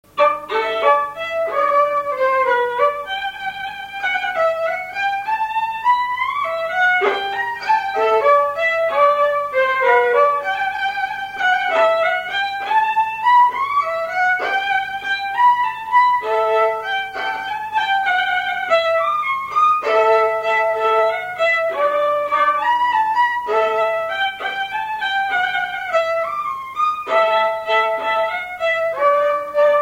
Valse
Résumé instrumental
gestuel : danse